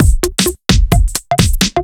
OTG_Kit 2_HeavySwing_130-B.wav